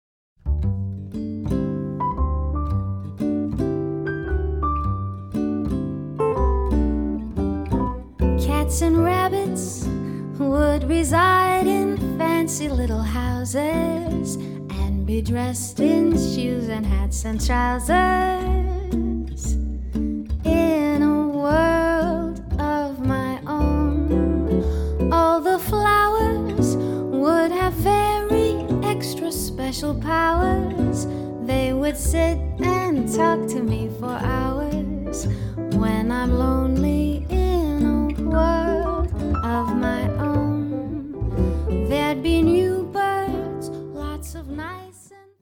カナダ人ジャズ・シンガー